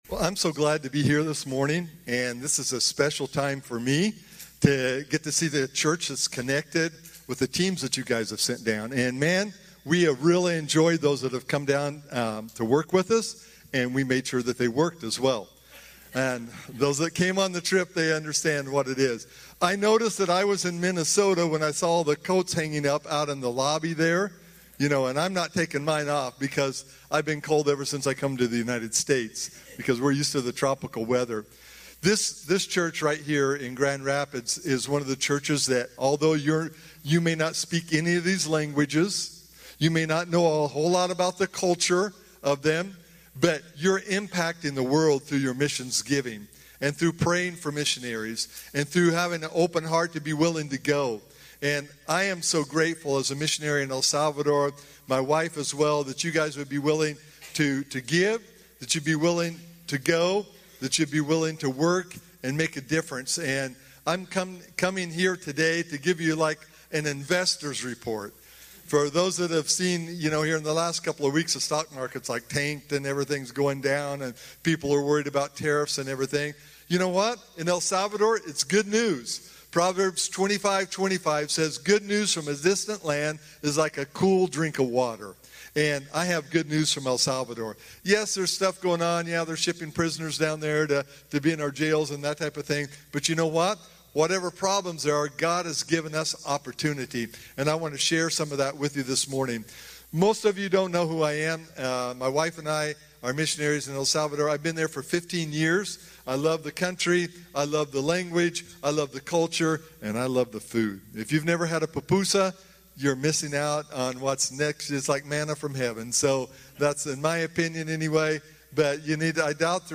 Some sermons that aren't in a normal series